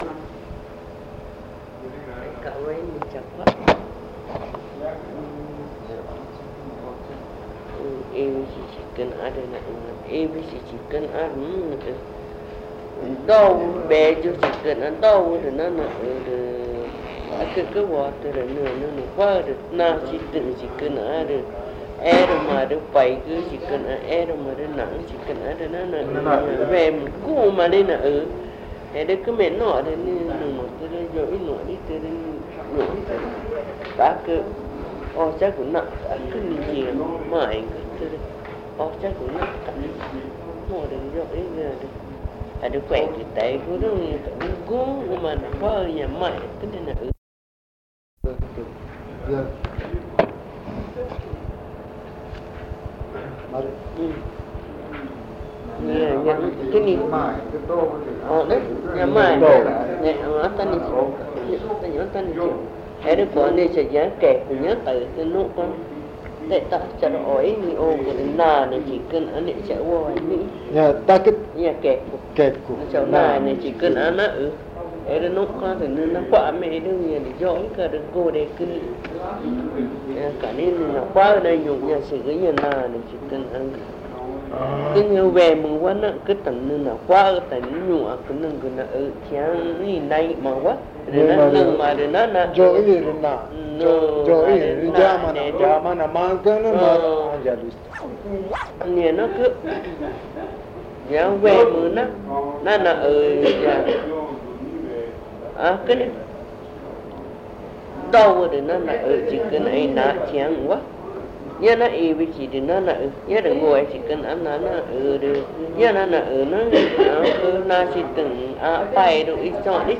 El 3 de diciembre de 2005 en Belém do Pará, en el museo Goldye, se realizó un trabajo